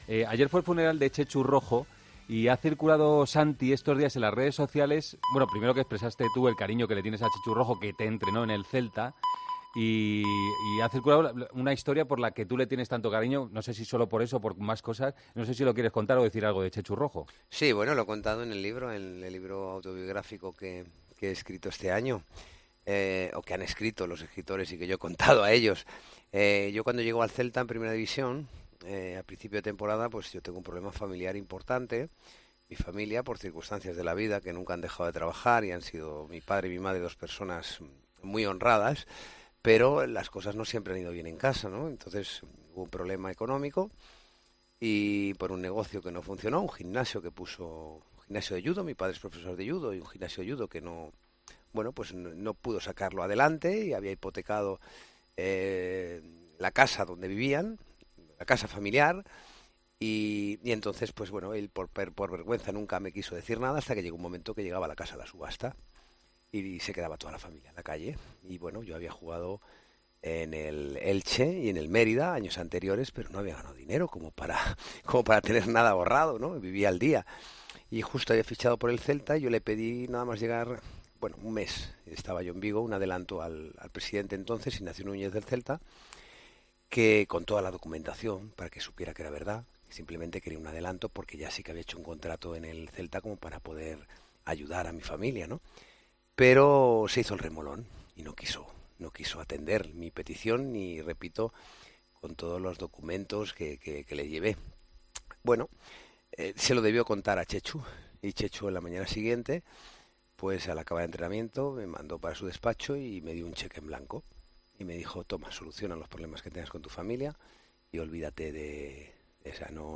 Tras el funeral de Txetxu Rojo, el exportero cuenta en 'El Partidazo de COPE' cómo su antiguo entrenador salvó a su familia de quedarse sin hogar, prestándole dinero.